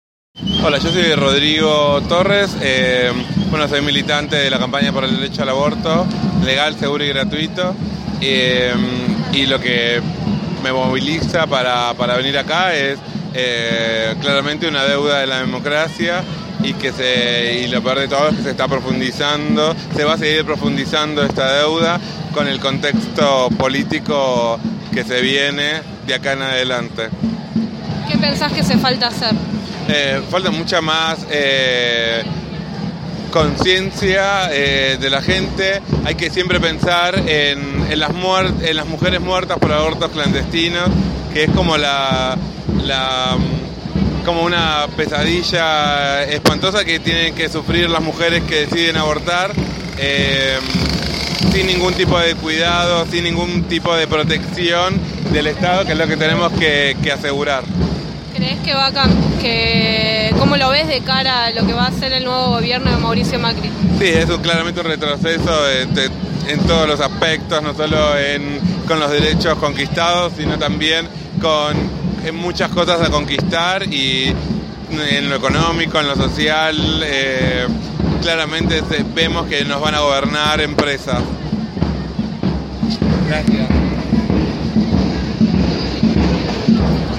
Es por ello que el Día Internacional de la Eliminación de la Violencia contra la Mujer se conmemoró frente al Congreso, en el que se dijo una vez más “Ni una menos”.